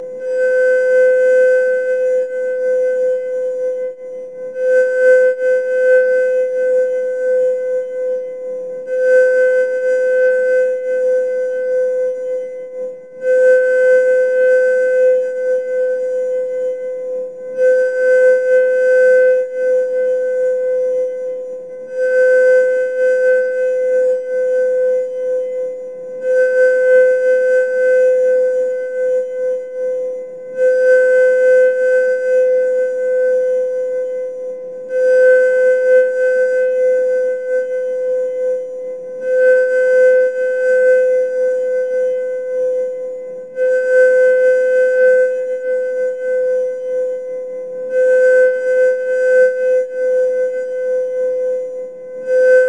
口琴无人机 " harmbgran
描述：用口琴创作的无人机。
Tag: 风能 声学环境 PR ocessed 无人驾驶飞机 样品 迷惑 口琴